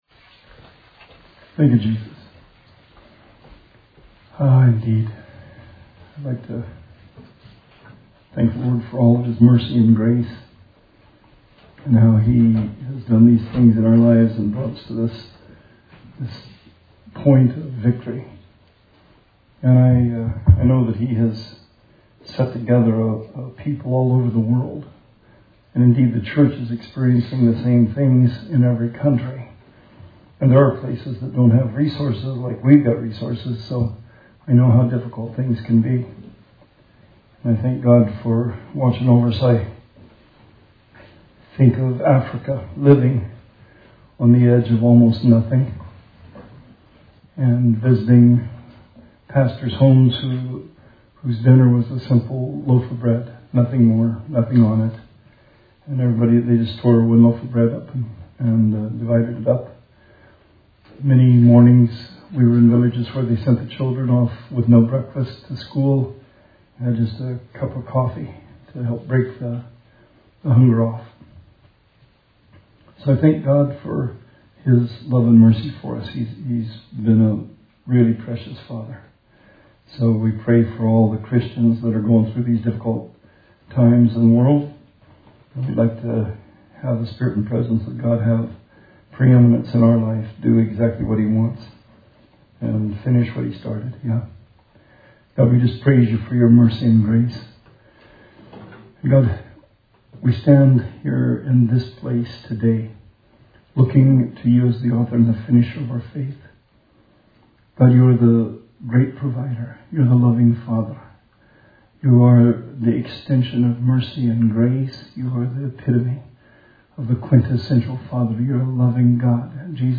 Sermon 5/3/20